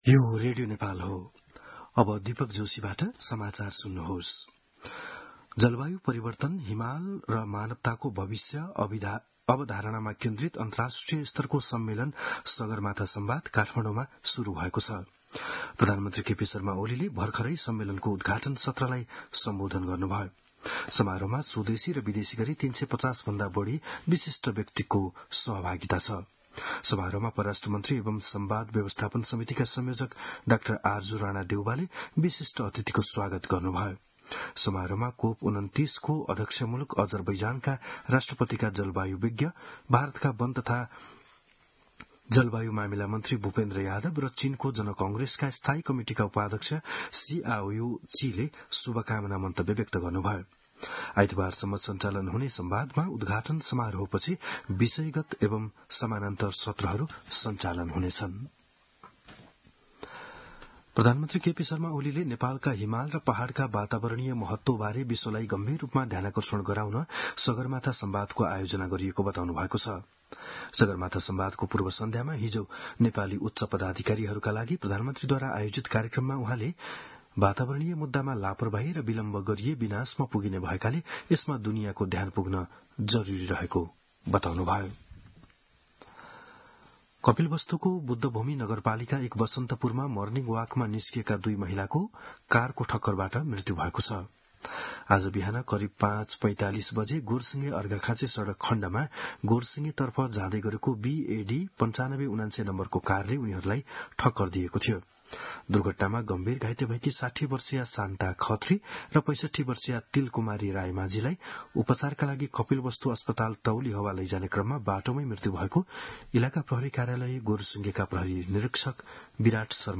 बिहान ११ बजेको नेपाली समाचार : २ जेठ , २०८२
11-am-news-1-3.mp3